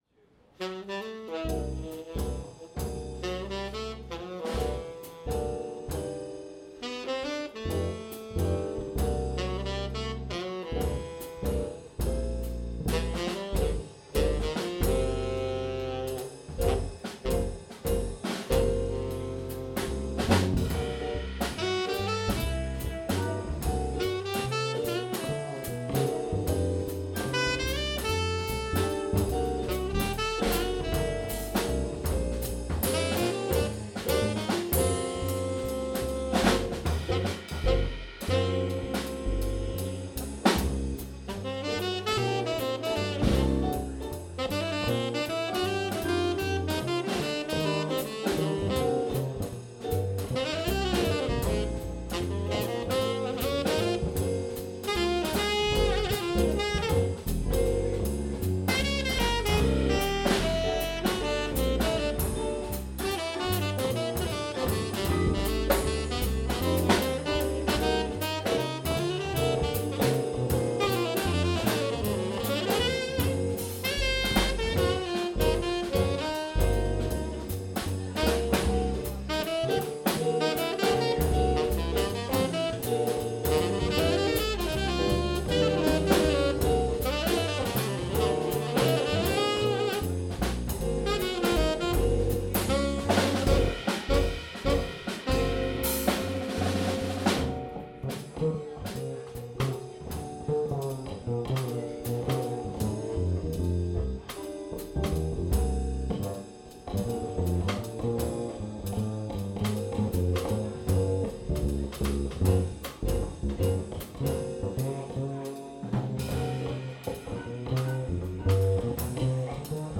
in 7/4 time